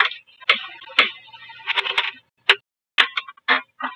Abstract Rhythm 34.wav